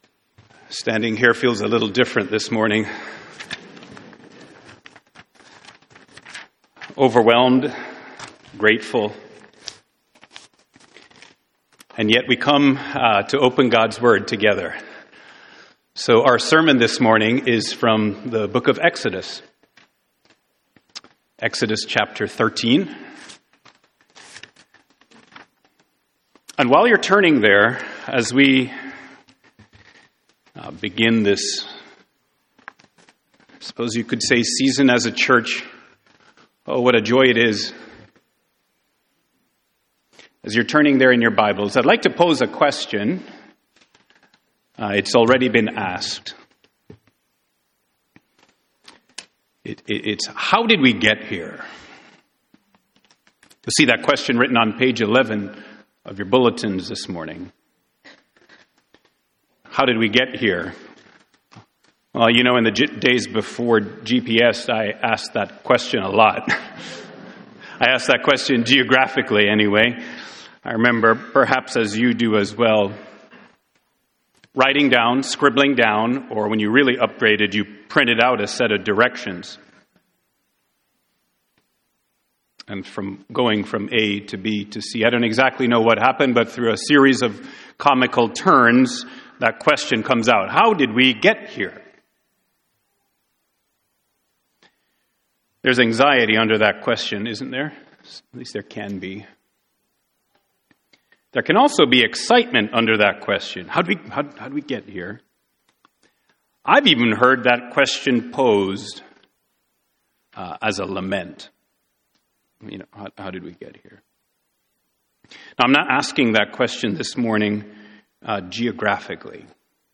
Series: Exodus — Audio Sermons — Brick Lane Community Church